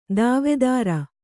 ♪ dāvedāra